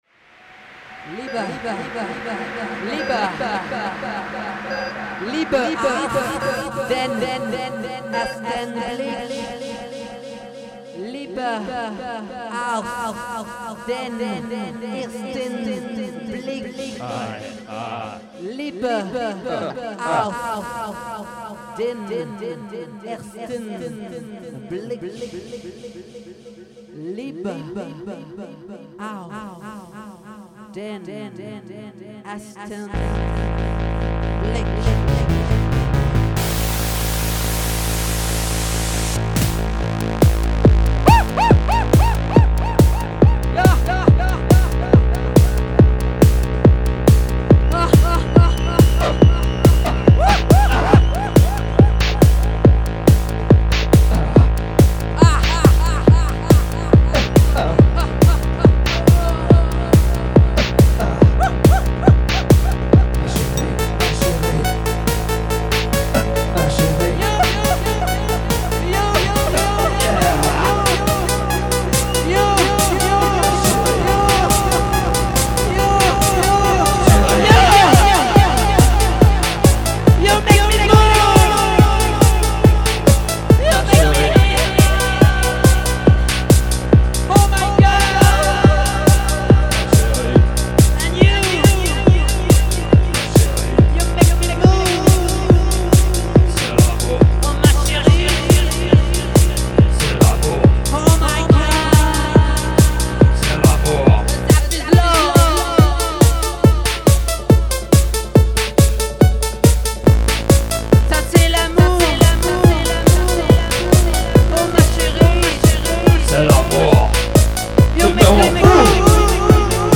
LIVE IN BLOCKAUS DY10